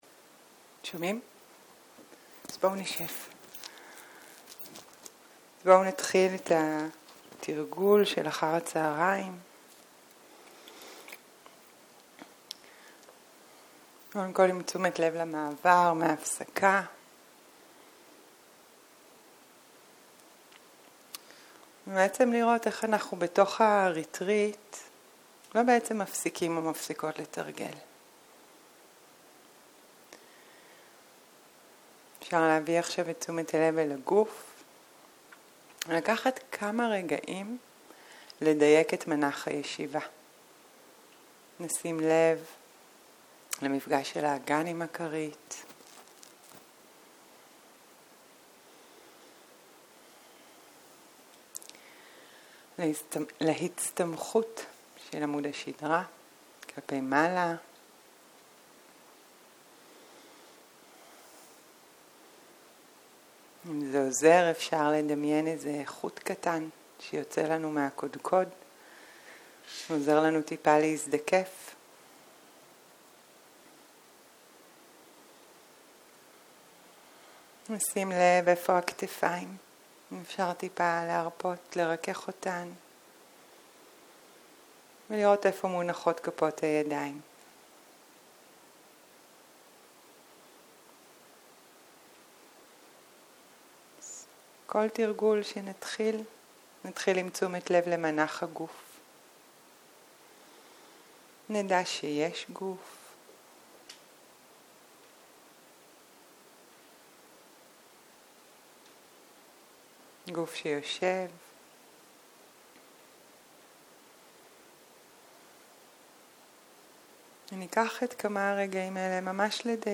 צהריים - מדיטציה מונחית
Guided meditation